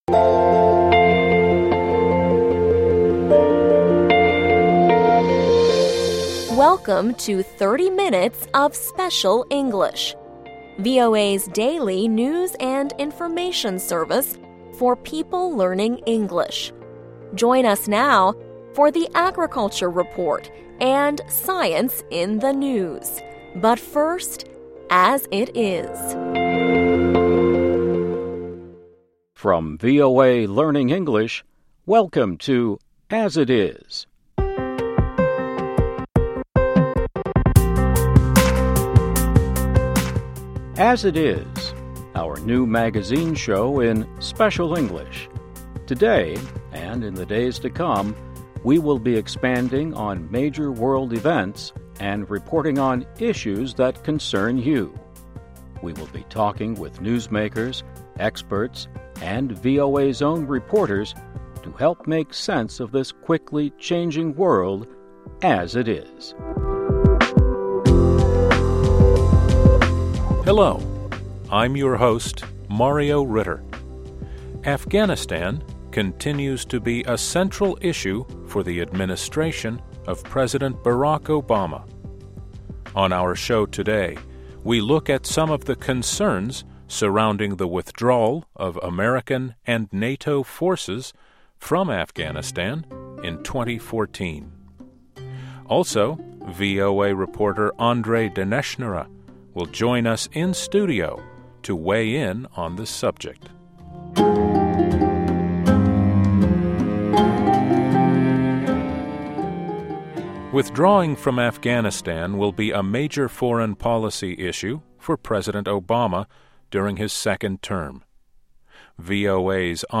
As It Is - The daily news program from VOA Learning English